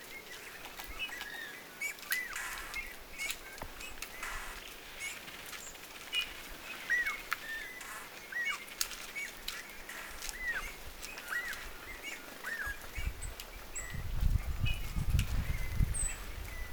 punatulkun ja pähkinähakin ääniä?
onko_siina_punatulkun_ja_pahkinahakin_aania.mp3